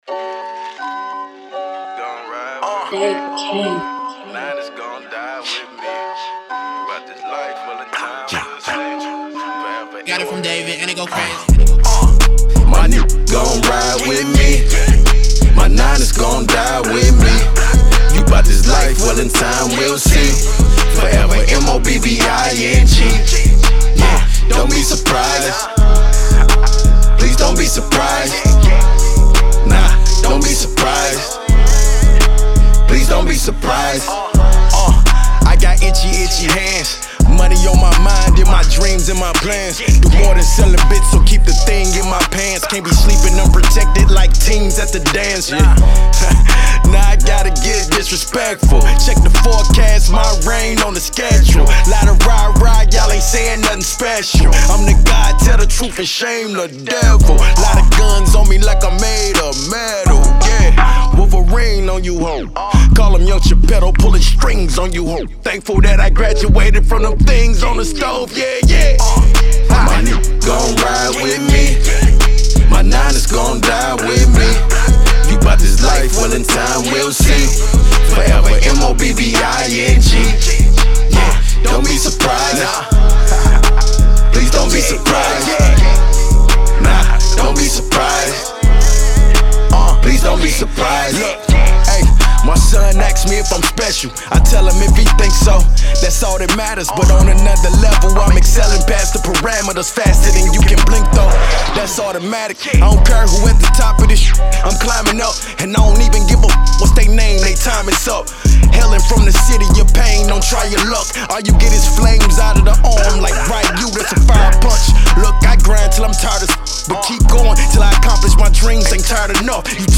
Hiphop
hip hop banger